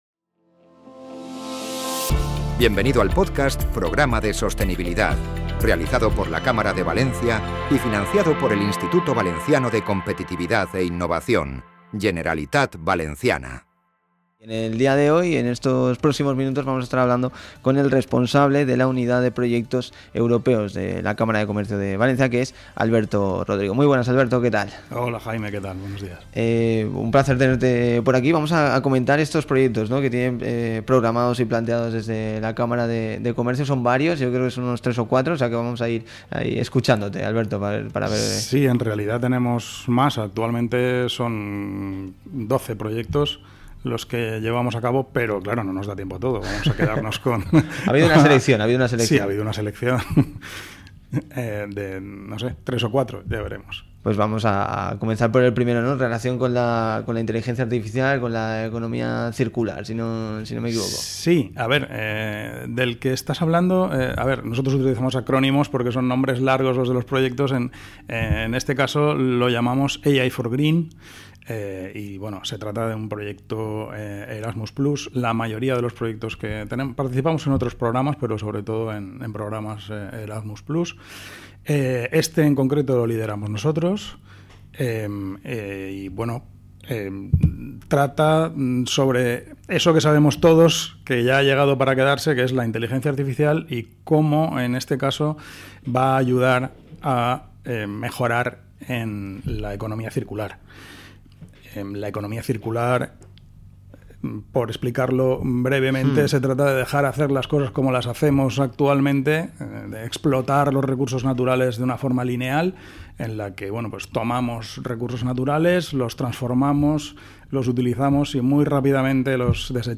Entrevista Unidas EU Projects Cámara Valencia